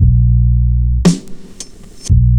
Index of /90_sSampleCDs/USB Soundscan vol.02 - Underground Hip Hop [AKAI] 1CD/Partition E/03-MIX LOOPS